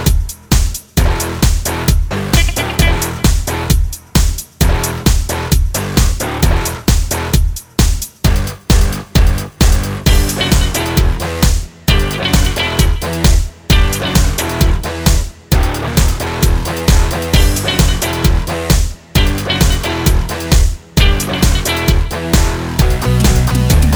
With Intro Pop (1990s) 3:30 Buy £1.50